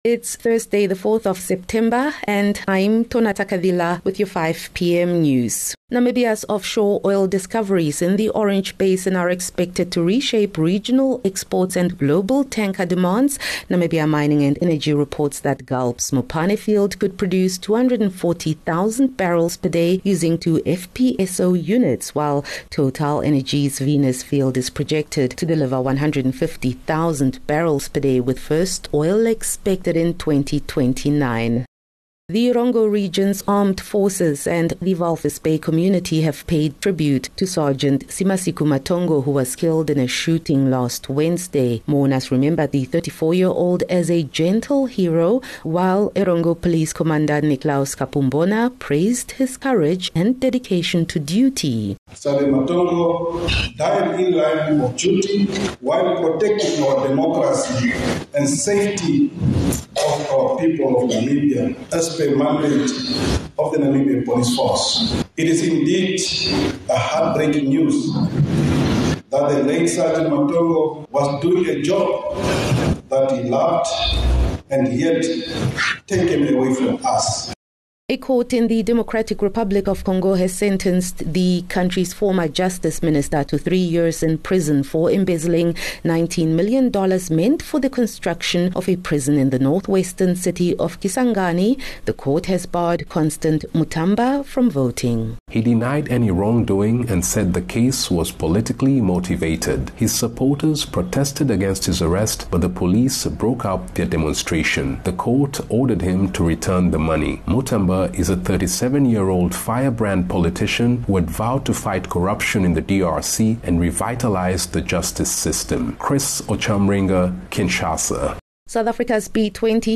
4 Sep 4 September - 5 pm news